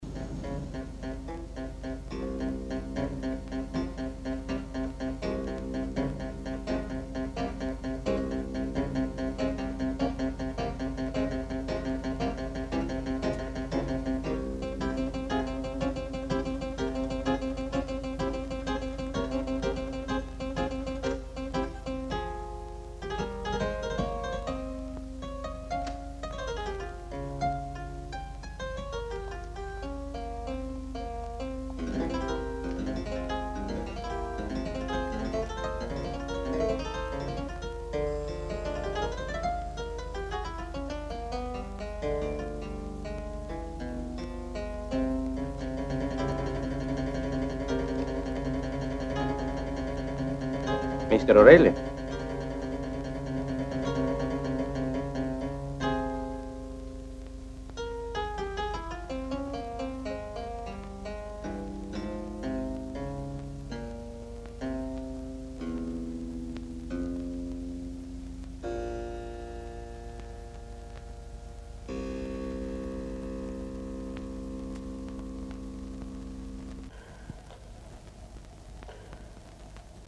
Клавесин-середина фильма